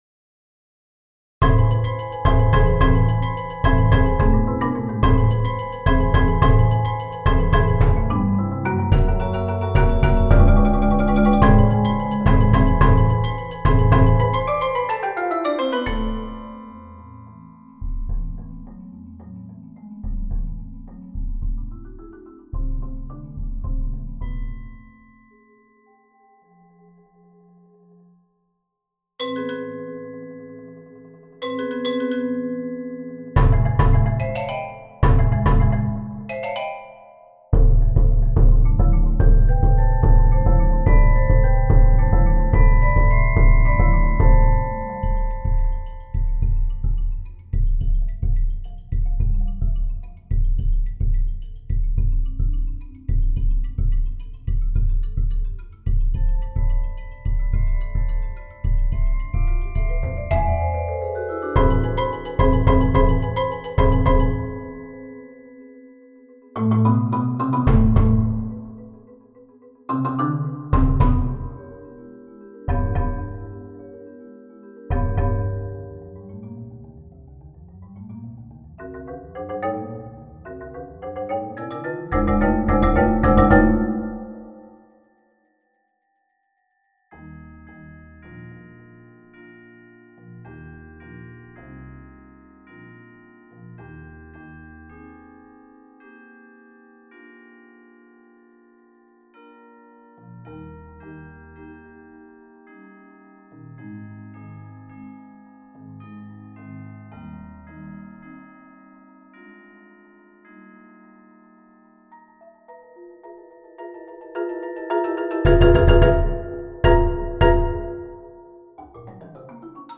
Voicing: Percussion Octet